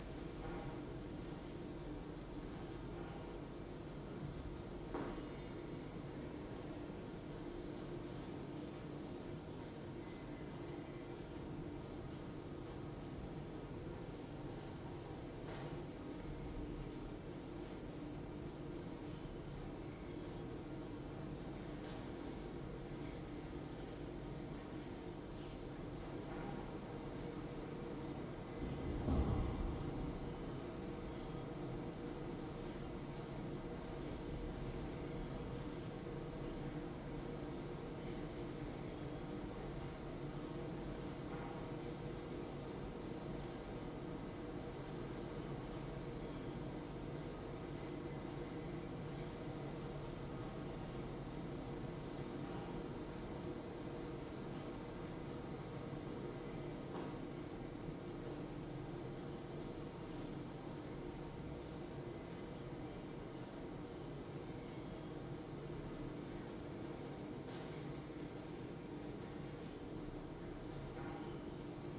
mindboost-rnbo-patch/Quiet Office_2020_10_22_Open Space Reaper Office.wav at 814da9bfaf64d9d1a8cd68d7a9afe2cd84471b2b